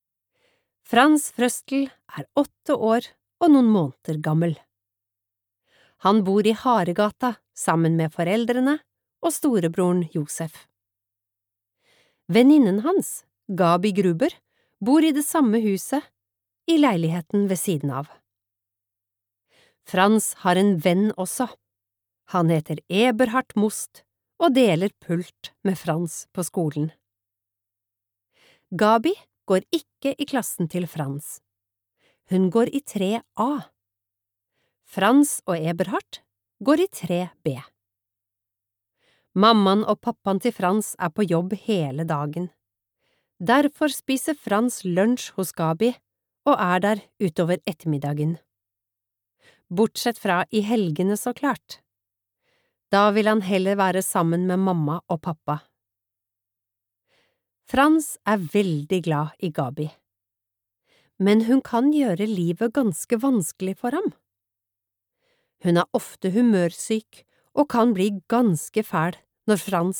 Nedlastbar lydbok